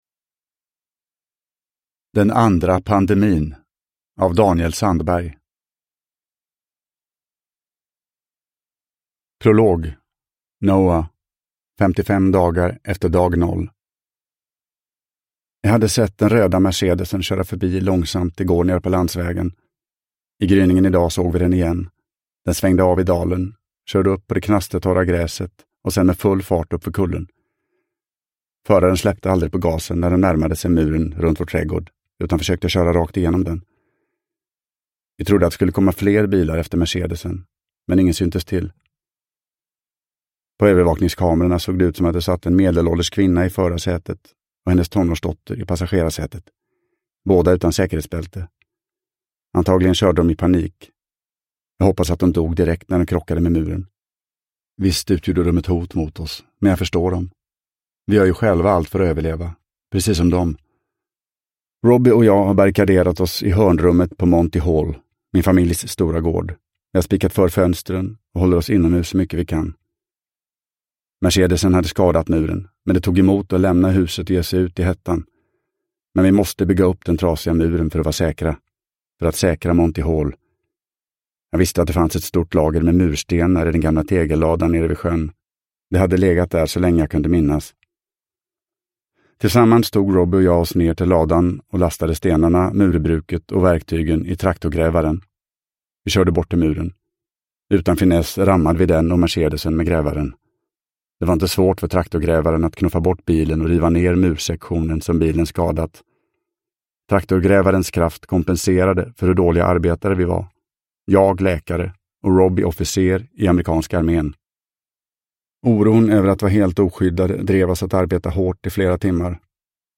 Den andra pandemin – Ljudbok – Laddas ner